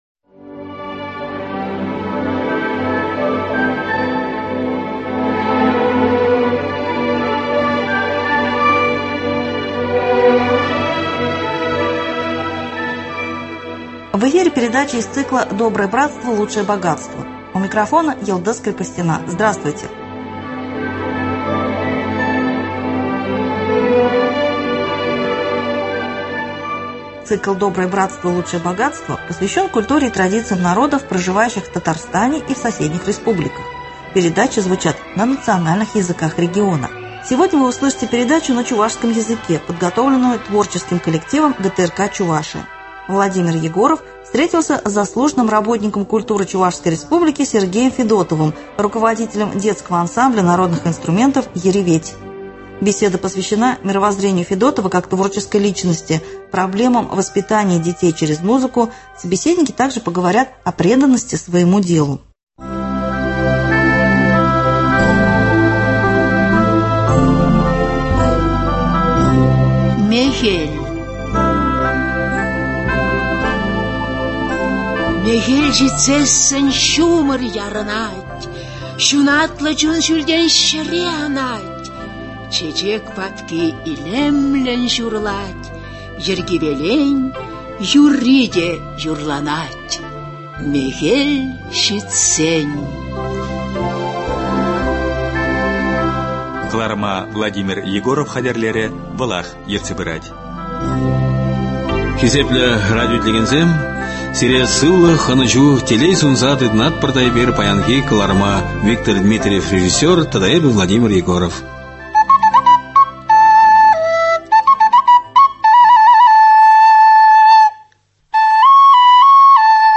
Цикл посвящен культуре и традициям народов, проживающих в Татарстане и соседних республиках, передачи звучат на национальных языках региона . Сегодня вы услышите фондовую передачу на чувашском языке, подготовленную творческим коллективом ГТРК Чувашия.